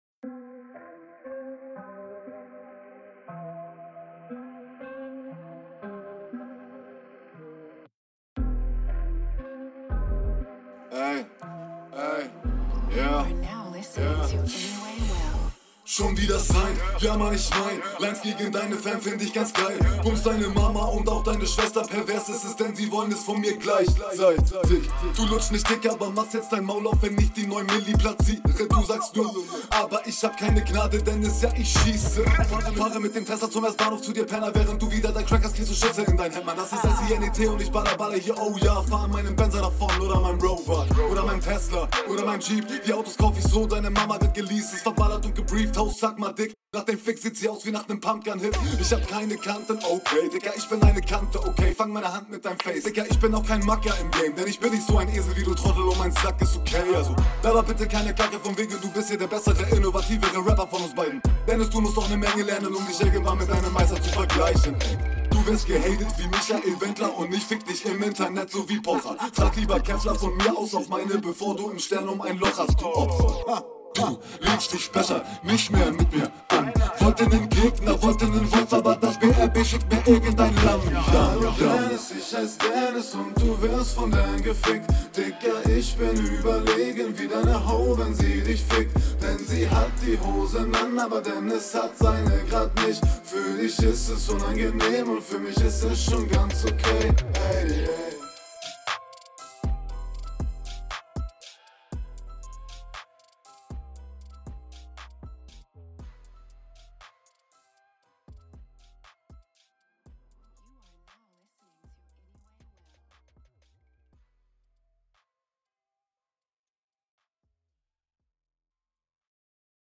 Du kommst stimmlich etwas kontrollierter und das gefällt mir fast besser als bei der HR! …
Flow und Mische auch wieder ziemlich gut, deine Punchlines fehlen aber leider wieder.